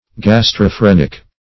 Search Result for " gastrophrenic" : The Collaborative International Dictionary of English v.0.48: Gastrophrenic \Gas`tro*phren"ic\, a. [Gastro- + -phrenic.]